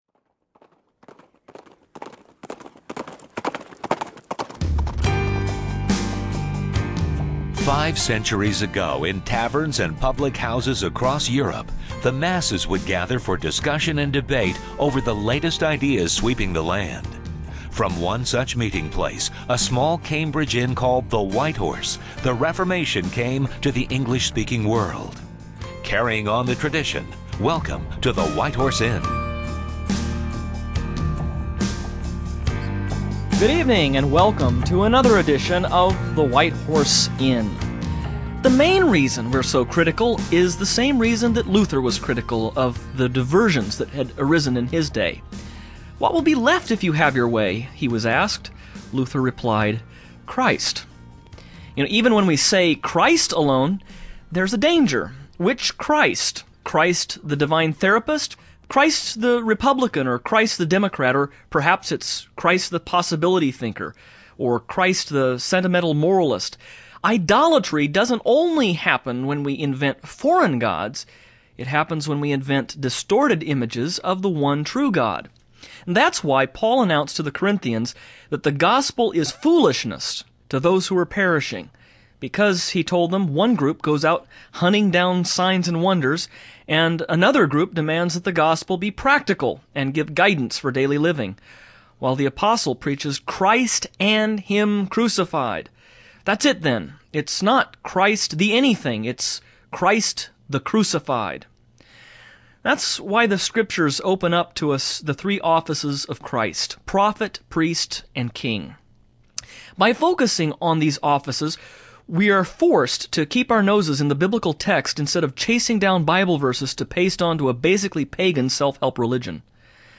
But what does scripture say that Jesus came to do? On this program the hosts will discuss the three offices of Christ, showing how he fulfilled the roles of prophet, priest and king.